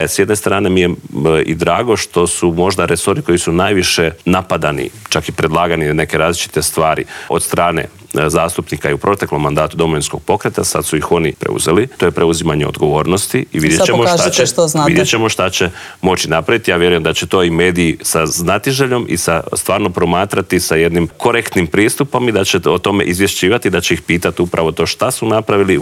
ZAGREB - Nakon što je predsjednik HDZ-a Andrej Plenković predao 78 potpisa i od predsjednika Zorana Milanovića dobio mandat da treći put zaredom sastavi Vladu, saborski zastupnik češke i slovačke nacionalne manjine Vladimir Bilek otkrio je u Intervjuu tjedna Media servisa tko je od manjinaca dao svoj potpis.